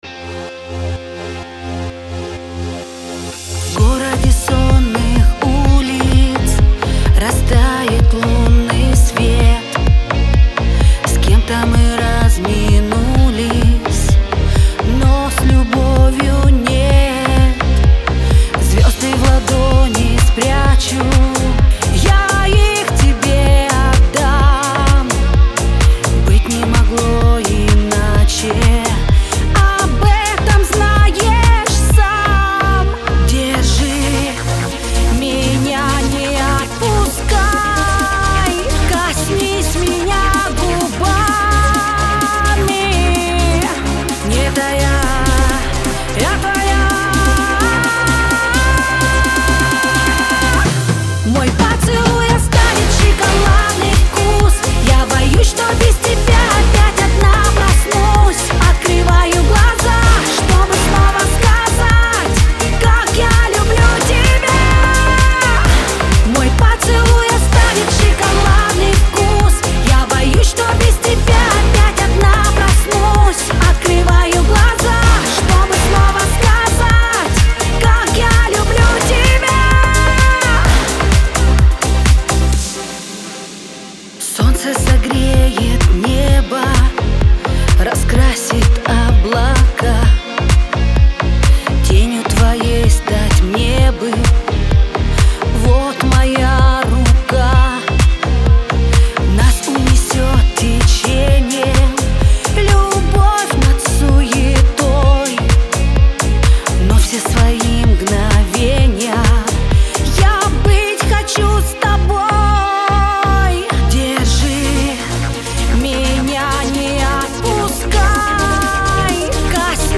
диско
pop